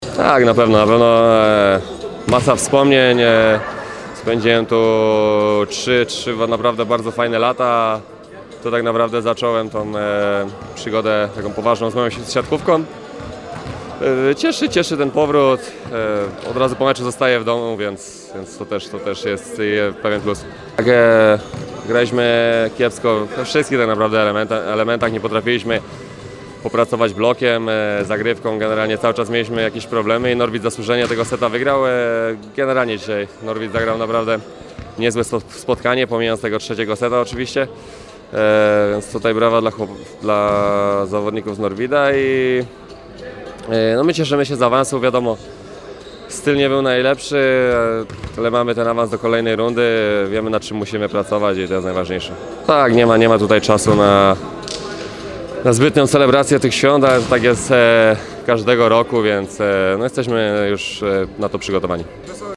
– My cieszymy się z awansu, styl może nie był najlepszy, ale jesteśmy w kolejnej rundzie – mówi Mateusz Bieniek, środkowy PGE Skry po meczu z Exact Systems Norwid Częstochowa w Pucharze Polski.